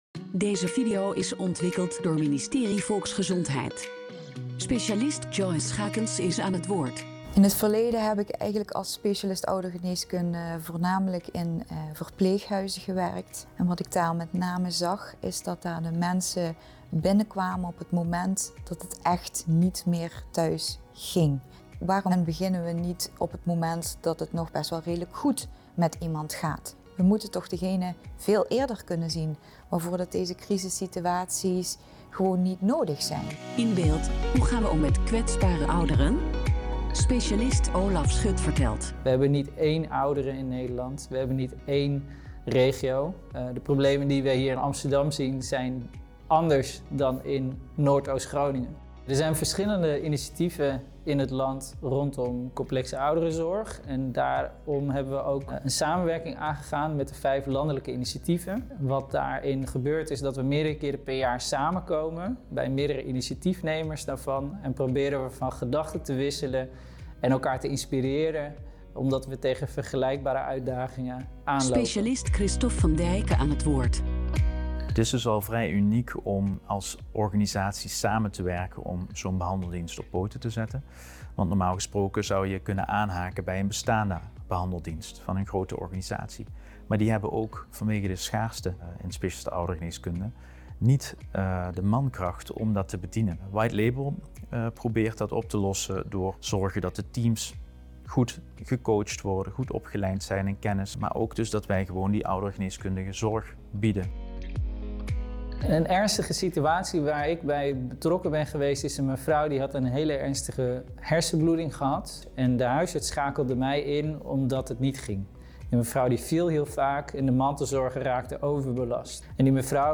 De specialist ouderengeneeskunde speelt hierbij een verbindende rol. In de video zijn er drie Specialisten Ouderengeneeskundig (SO) aan het woord.